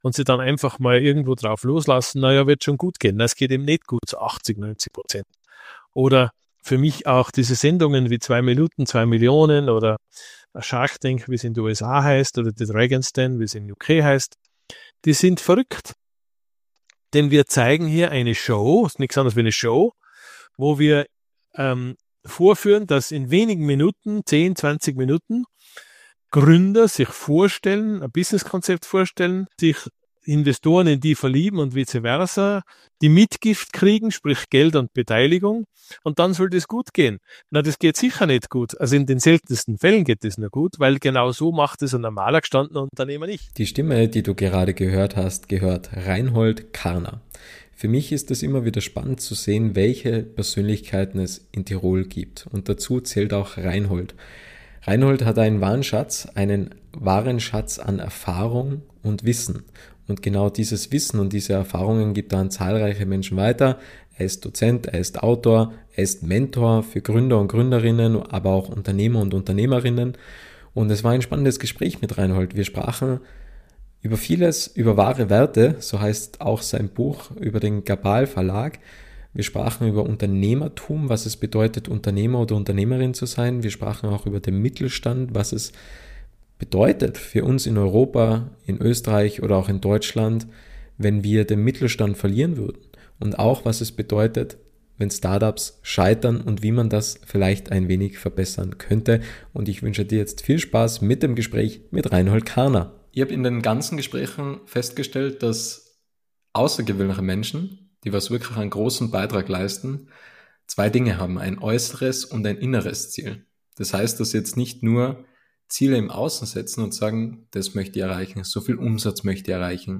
Im Podcast-Interview